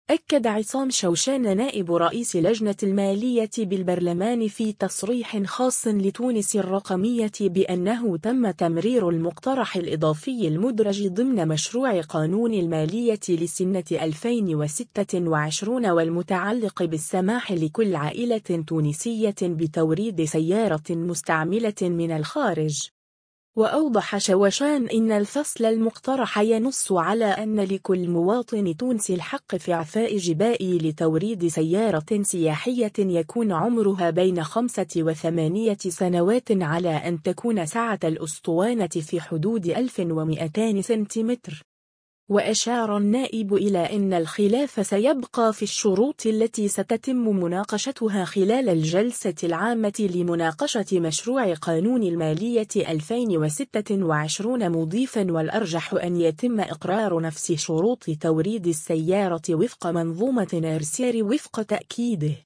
أكد عصام شوشان نائب رئيس لجنة المالية بالبرلمان في تصريح خاص لـ”تونس الرقمية” بأنه تمّ تمرير المقترح الإضافي المدرج ضمن مشروع قانون المالية لسنة 2026 والمتعلق بالسماح لكل عائلة تونسية بتوريد سيارة مستعملة من الخارج.